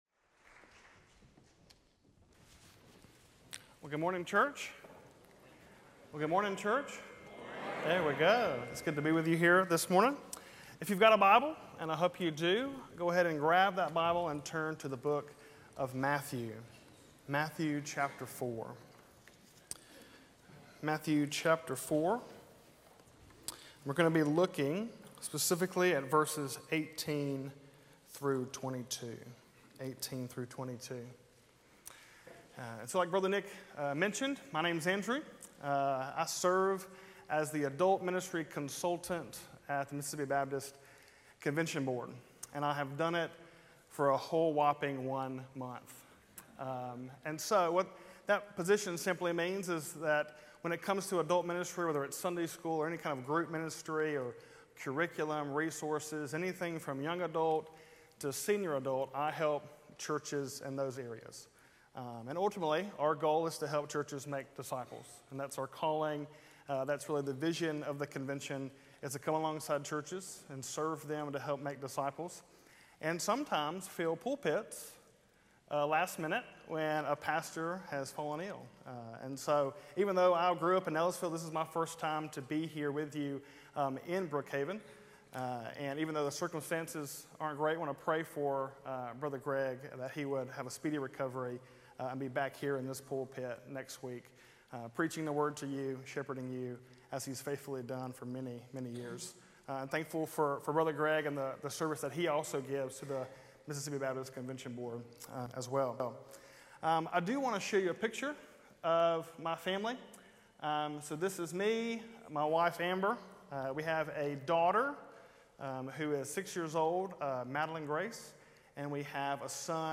Sermons
July-7-2024-Sermon-Audio.mp3